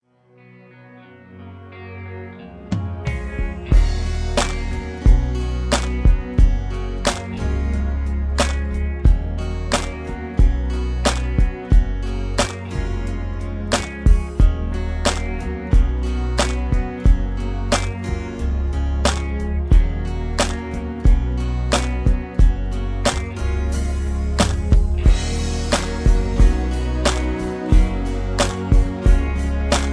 (Key-Gb) Karaoke MP3 Backing Tracks
Just Plain & Simply "GREAT MUSIC" (No Lyrics).